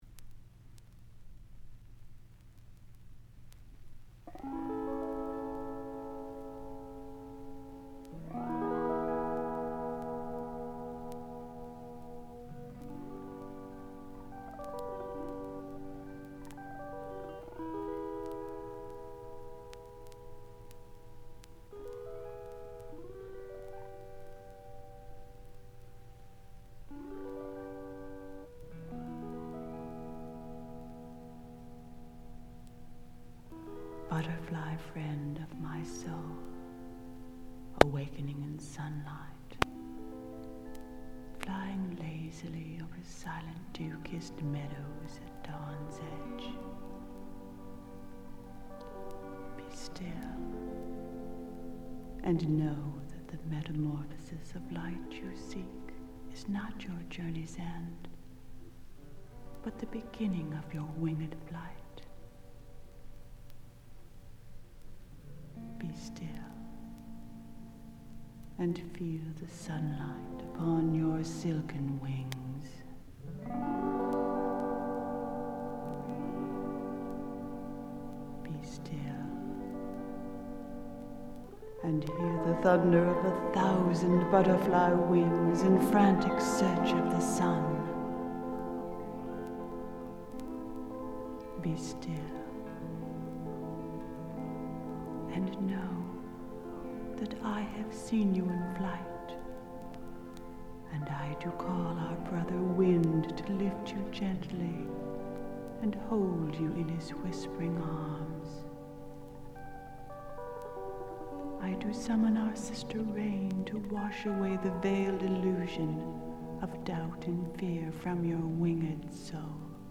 folk-psych